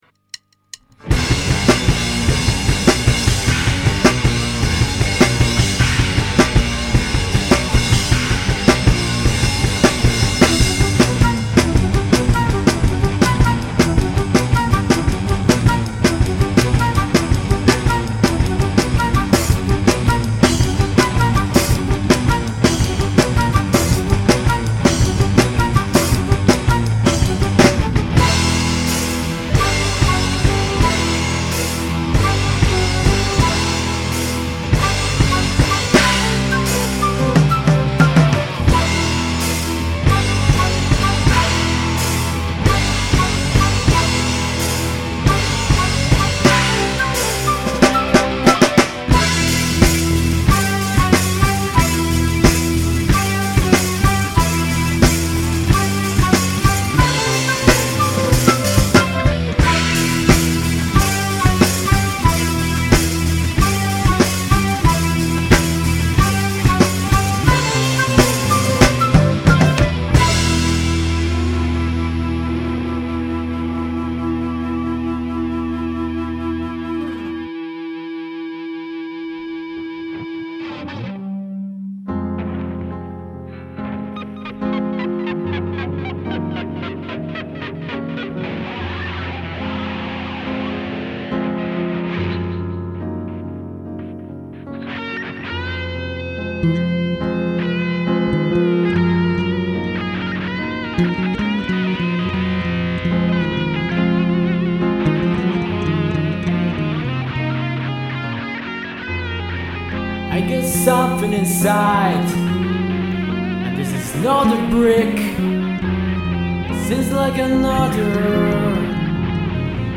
laboratorio di musica d'insieme
chitarra e voce
basso elettrico
batteria